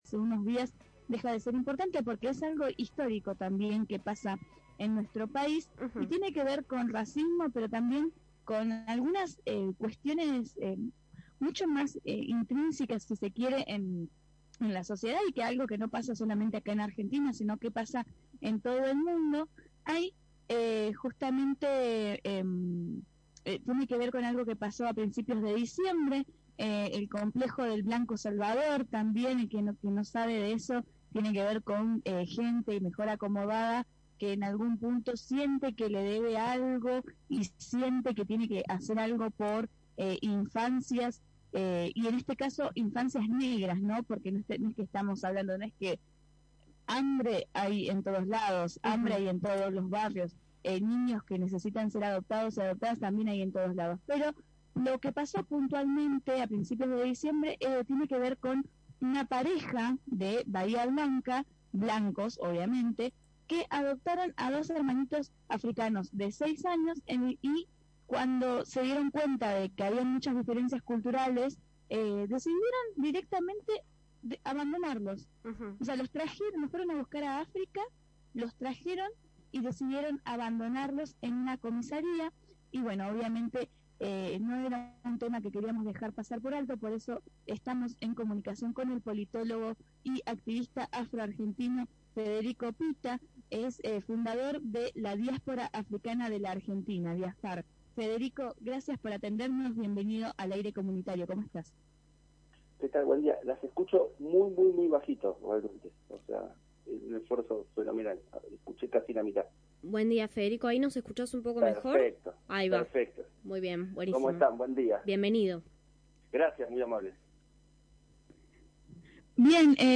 En Achatame la curva entrevistamos